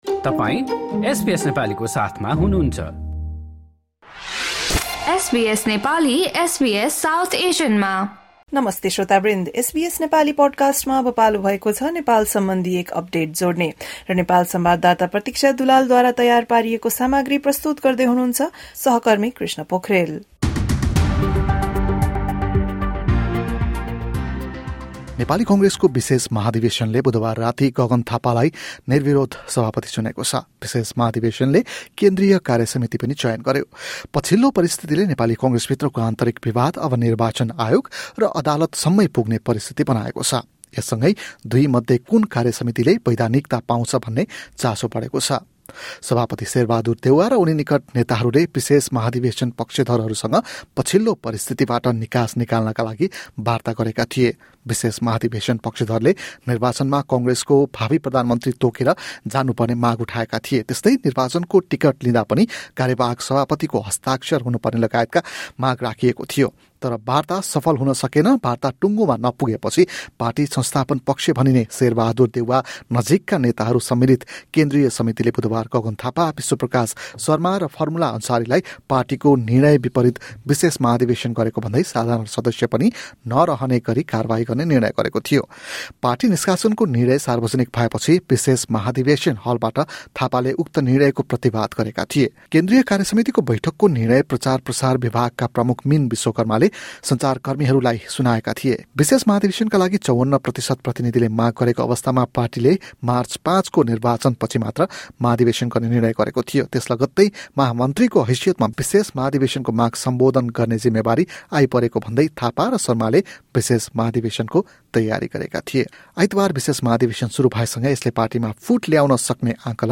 रिपोेर्ट सुन्नुहोस् नेपाल अपडेट: काँग्रेसको विशेष महाधिवेशनबाट गगनकुमार थापा सभापति निर्वाचित 02:58 विशेष महाधिवेशन पक्षधरले निर्वाचनमा काँग्रेसको भावी प्रधानमन्त्री तोकेर जानु पर्ने माग उठाएका थिए।